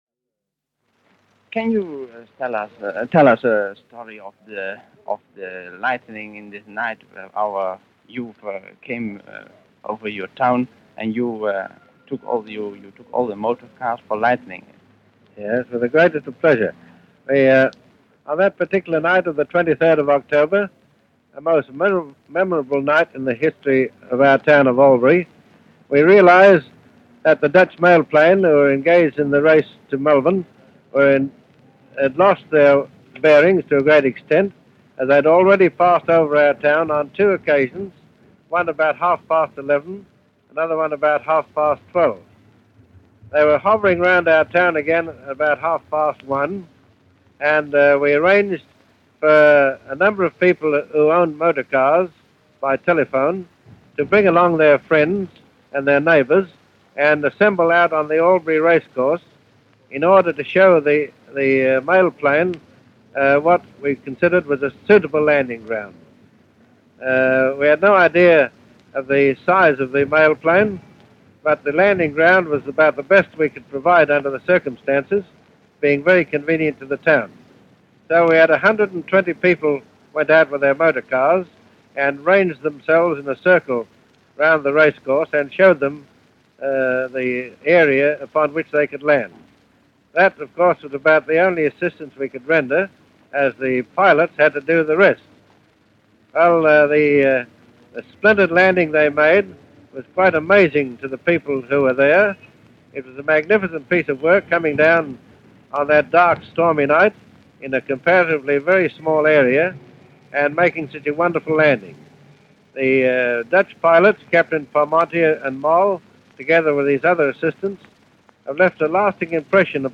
Listen to Mayor Alfred Waugh in a radio broadcast made during his visit to the Netherlands in 1935.
Alf-Waugh-Netherlands-1935.mp3